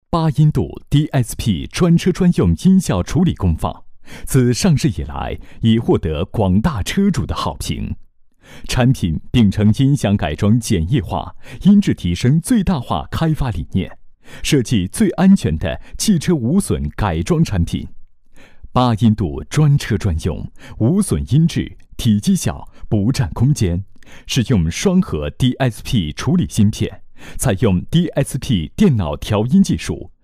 男87号-产品配音-清澈 生动-0511